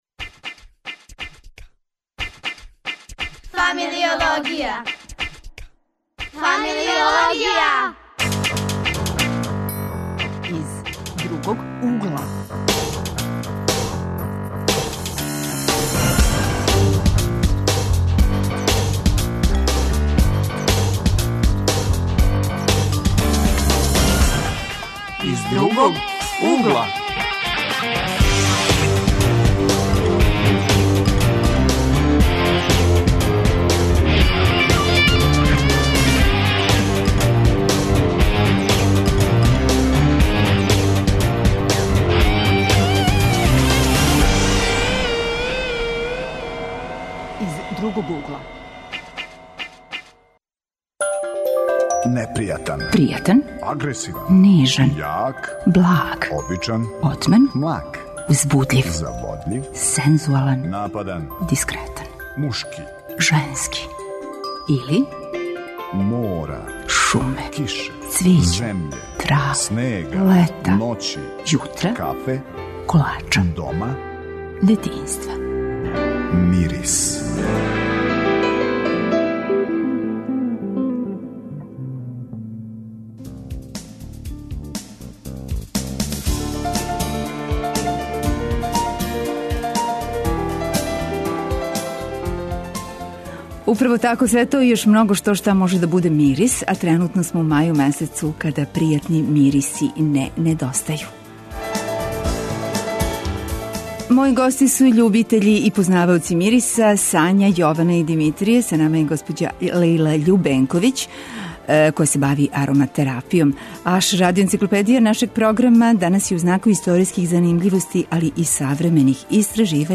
Гости су нам студенти и средњошколци, љубитељи и познаваоци мириса